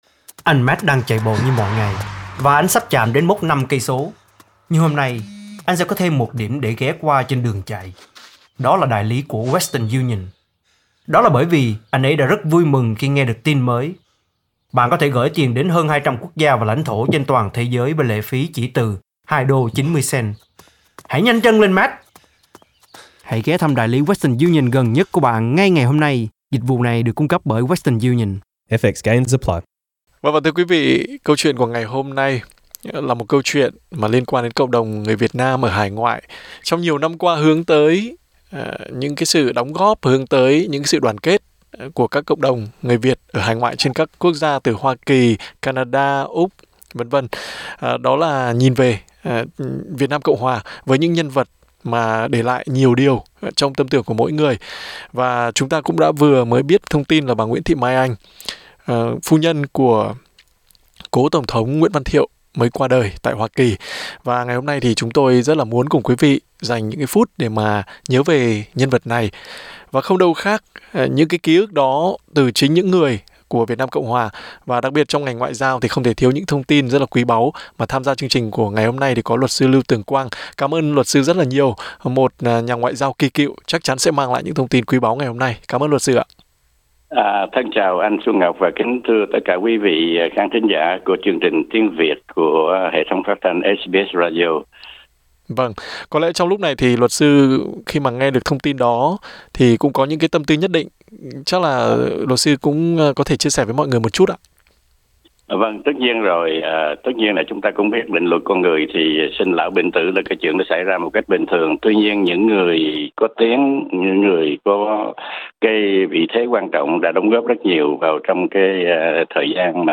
Trả lời phỏng vấn SBS Tiếng Việt